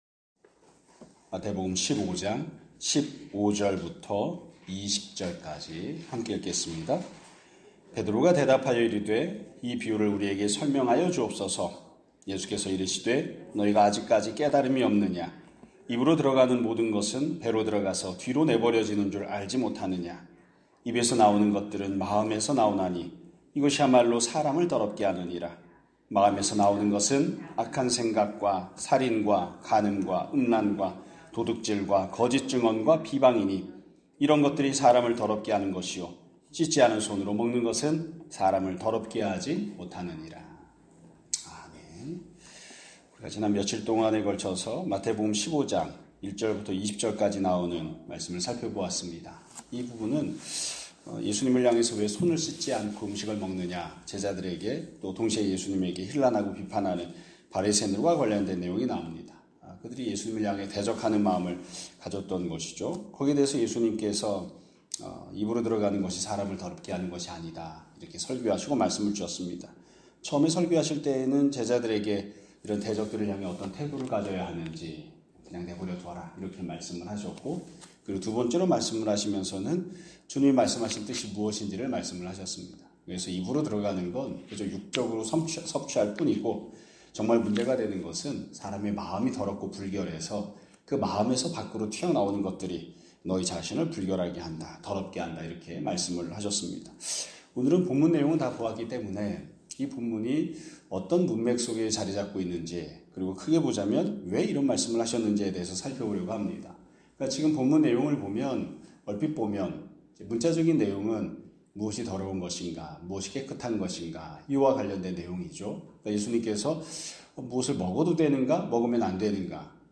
2025년 11월 5일 (수요일) <아침예배> 설교입니다.